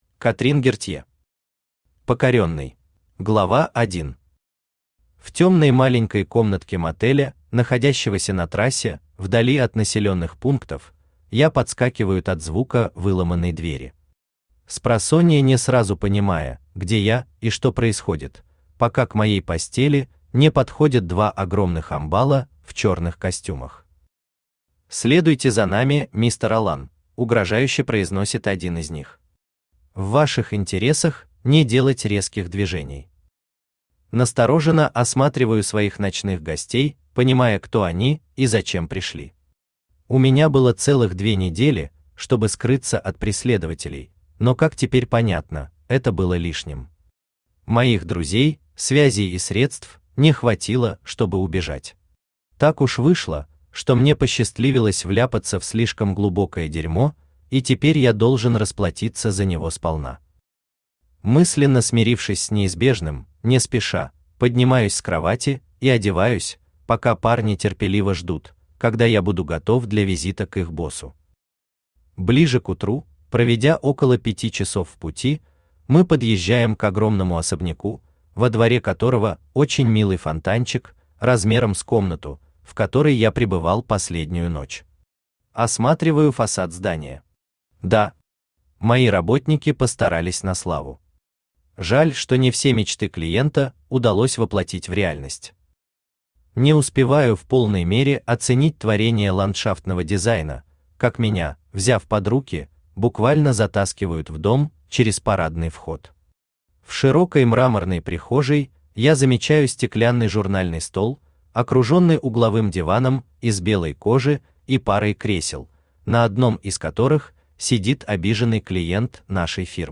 Аудиокнига Покорённый | Библиотека аудиокниг
Aудиокнига Покорённый Автор Катрин Гертье Читает аудиокнигу Авточтец ЛитРес.